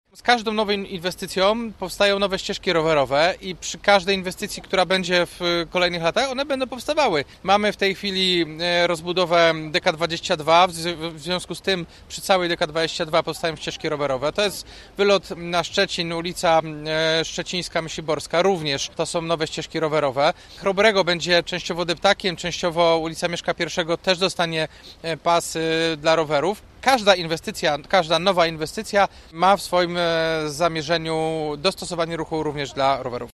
Obecnie w Gorzowie jest 57 kilometrów ścieżek rowerowych i z każdą inwestycją będzie ich przybywać – mówi prezydent Gorzowa Jacek Wójciki: